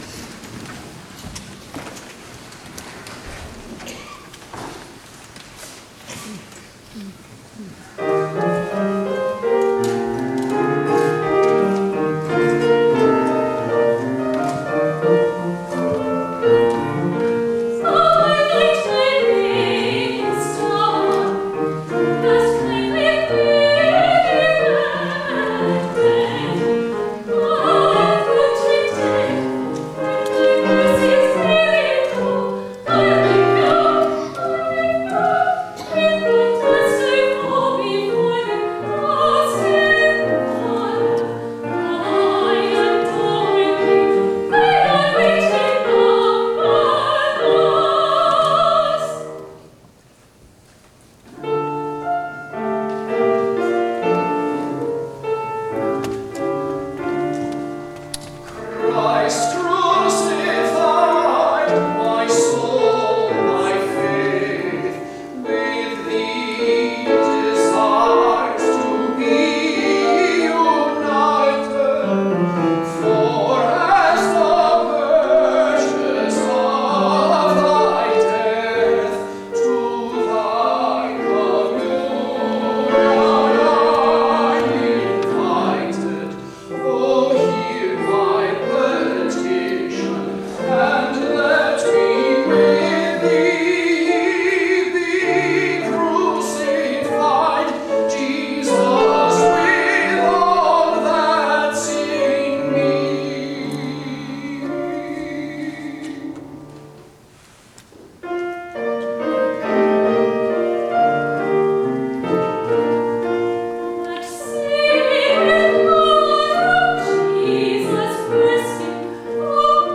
On Sunday, September 9, 2018, the Lititz Moravian Collegium Musicum presented an inaugural concert in front of a full house.